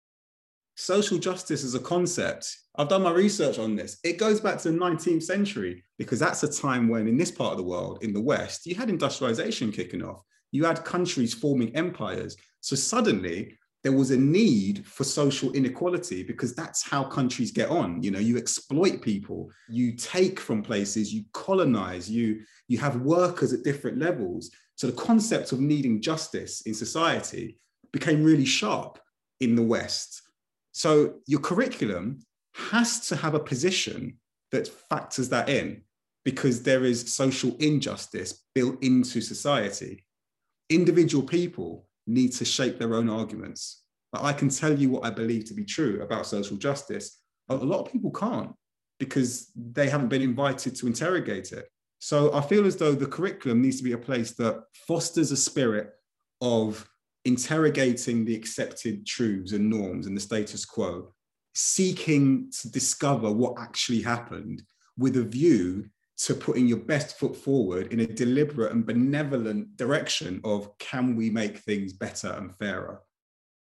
The purpose is allow players to understand the message of the speech without listening to it over and over, as the speech is short and quick, but has a very important message to convey about social justice and questioning, or interrogating, the narrative taught to us.
In addition, bullet hell games gave me a sense of the manageable chaos of dodging multiple projectiles at once, as in both audios, the presentors speak at a relatively quick speed with little pauses.